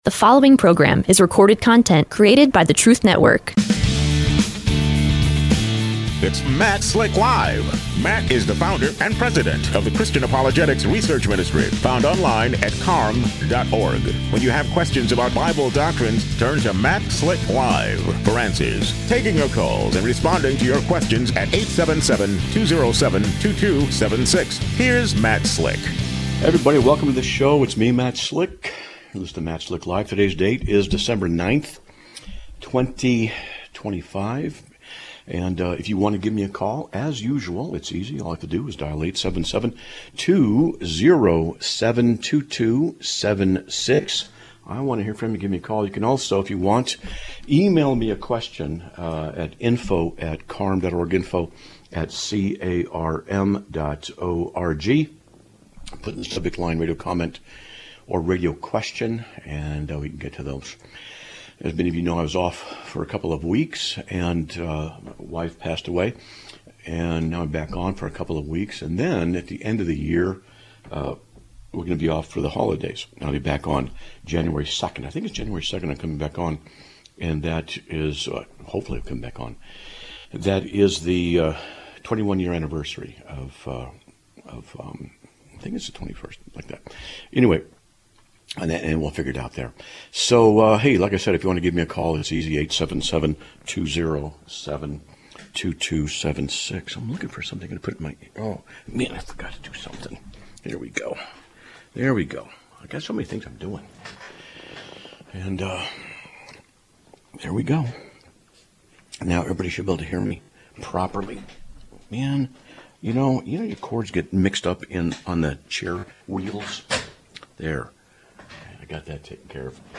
Live Broadcast of 12/9/2025